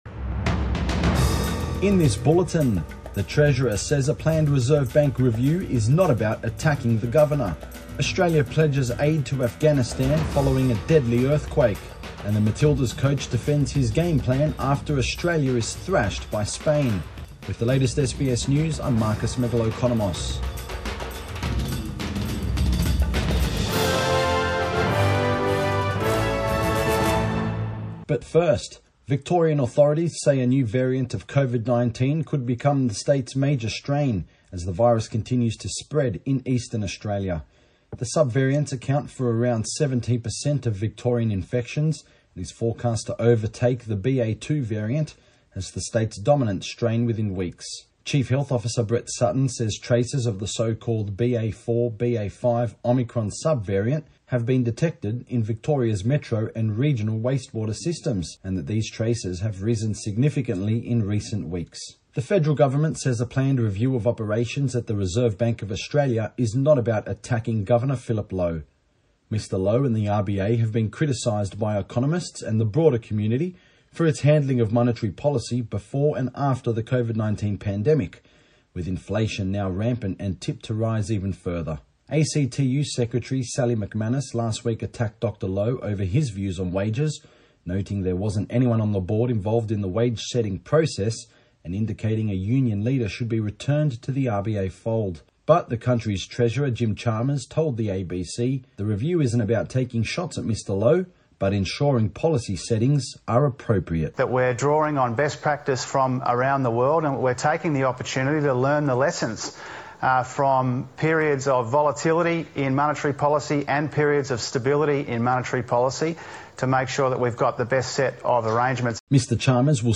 PM bulletin 26 June 2022